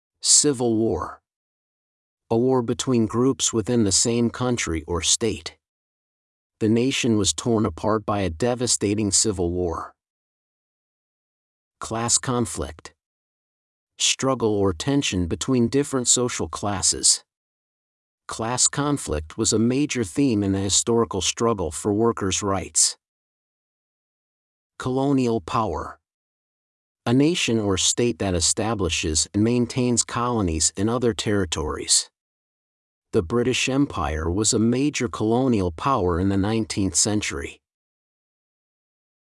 A list of important phrases with an MP3 recording